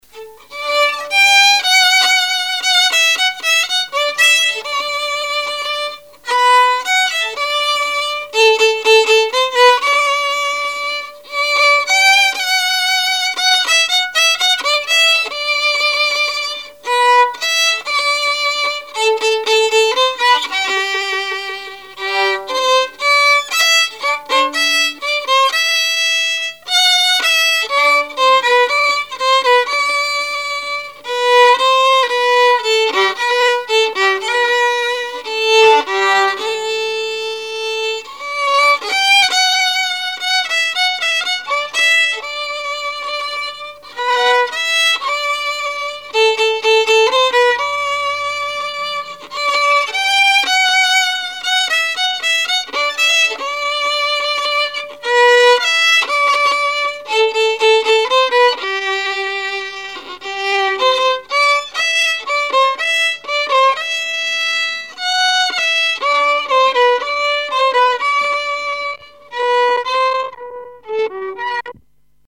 danse : tango
Répertoire musical au violon
Pièce musicale inédite